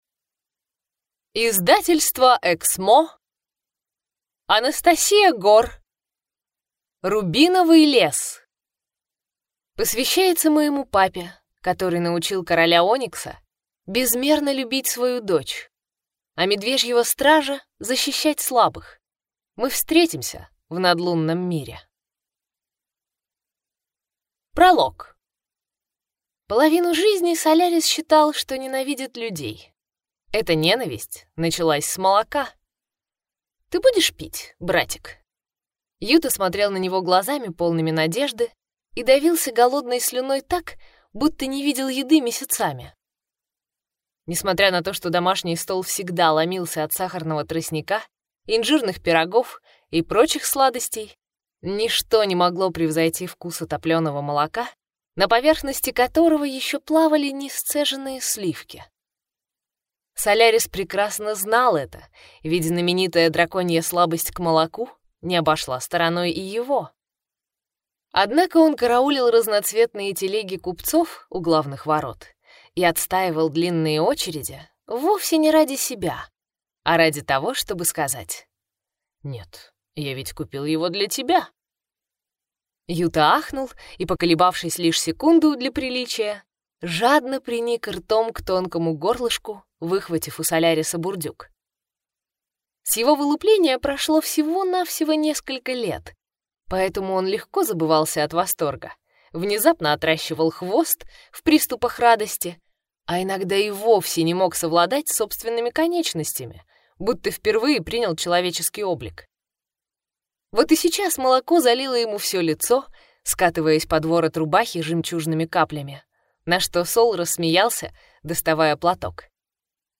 Аудиокнига Рубиновый лес | Библиотека аудиокниг